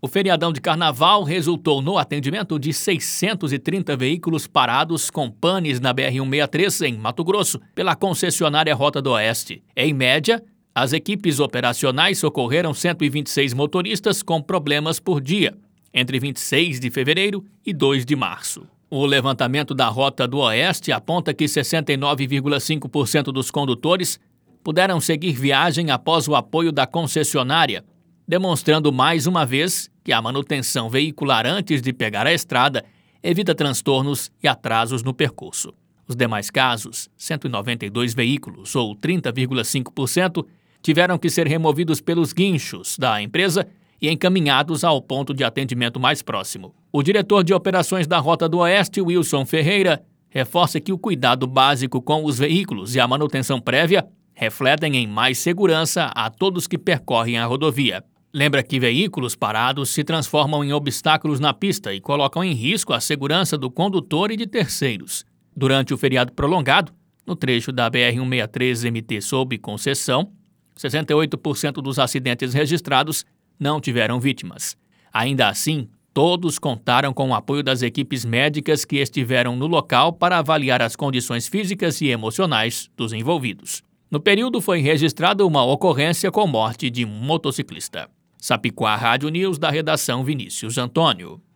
Boletins de MT 04 mar, 2022